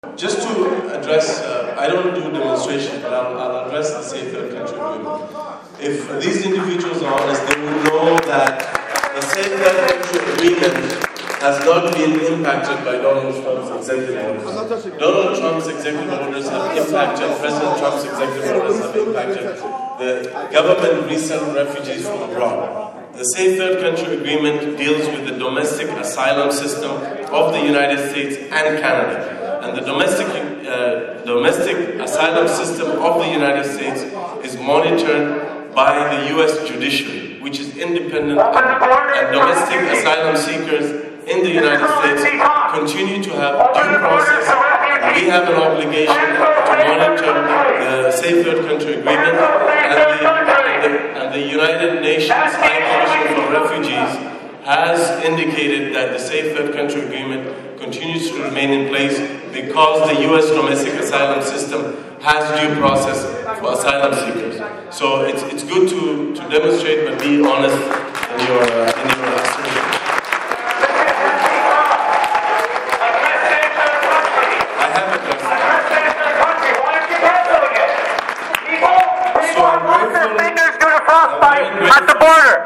Federal Immigration Minister Ahmed Hussen delivers a speech to the participants of the 19th National Metropolis Conference in Montreal while protesters try to interrupt his address and demand Canada scrap its Third Safe Country agreement with the U.S. on Friday, March 17, 2017.
“If these individuals are honest, they will know the Third Safe Country agreement has not been impacted by Donald Trump’s executive orders,” Hussen said straining to be heard over the shouts of protesters.